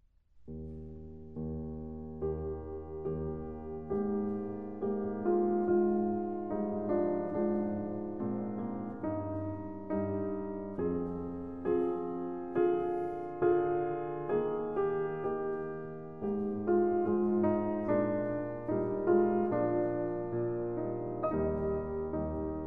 To examine the performance of the non-linear compression, I will use a real (piano music) example to showcase the effects of linear/non-linear dynamic compression. As a test sample, here is a rather calm intro to a piece by J.S. Bach. First the original, 16 bit:
bach_kurtag_original.wav